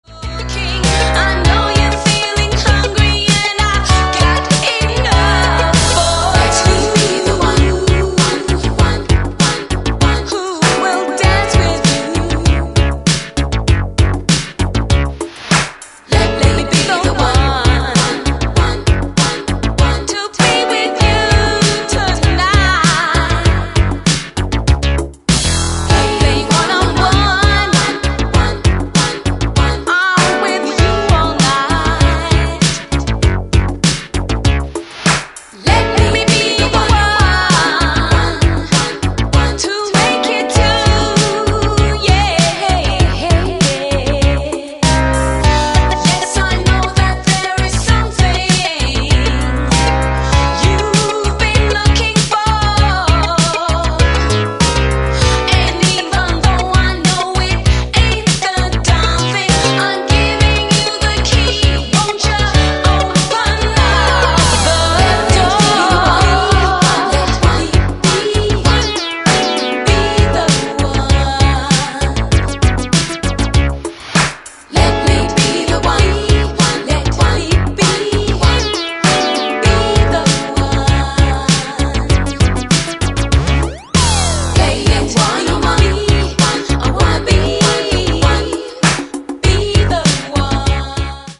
Electro tinged street soul
That sultry voice?
rarefied street soul gear